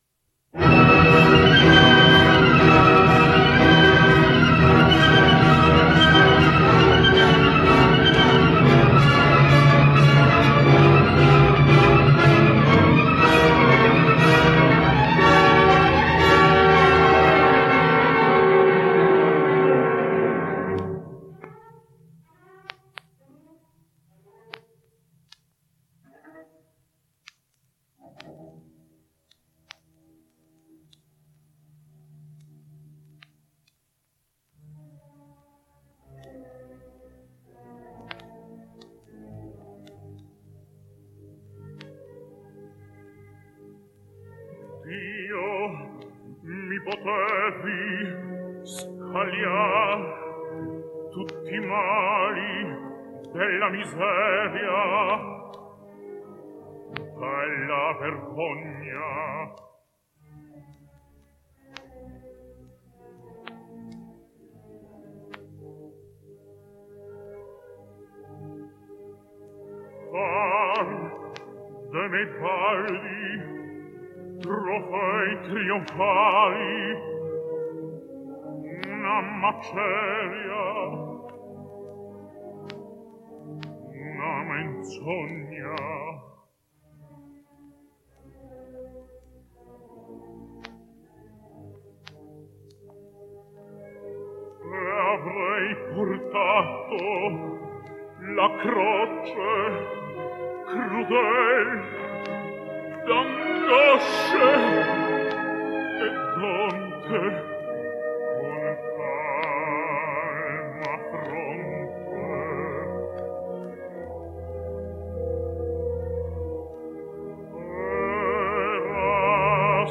Romanian Tenor
Dio Mi Potevi Scagliar / Ottello / 1977 – Ludovic Spiess